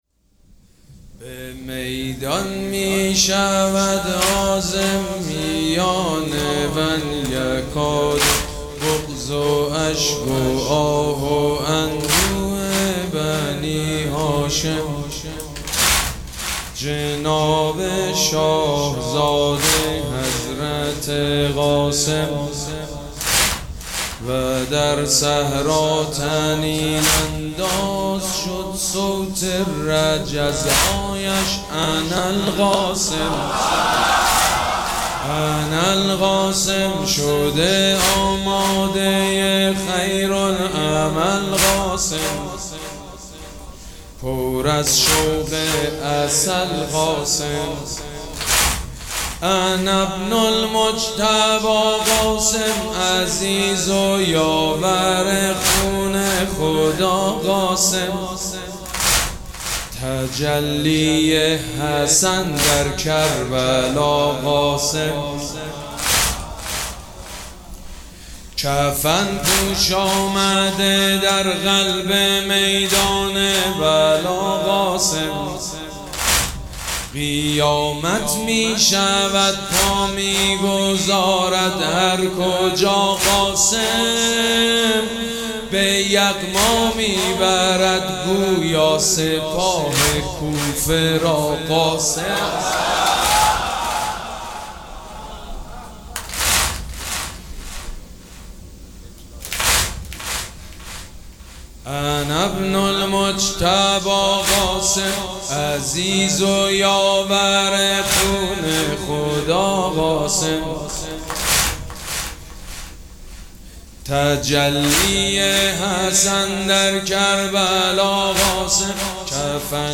مراسم عزاداری شب ششم محرم الحرام ۱۴۴۷
حاج سید مجید بنی فاطمه